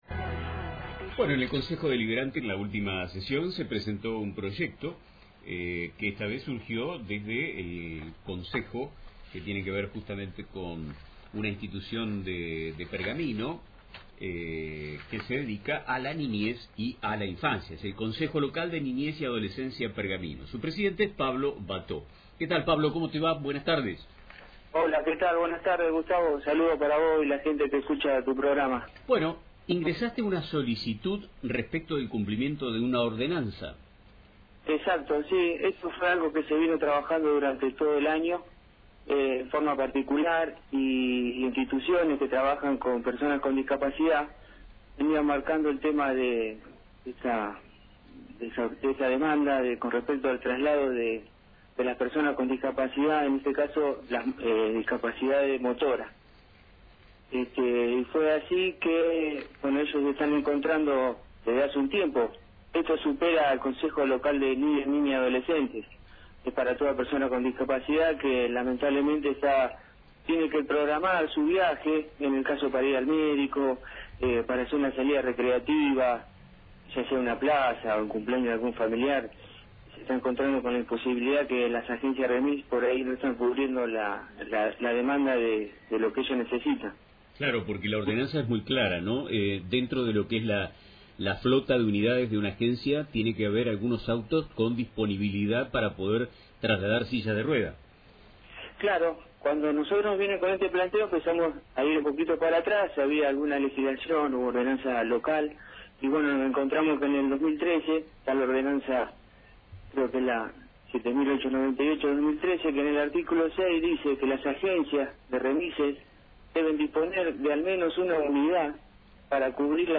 en diálogo con Nuestro Tiempo de Radio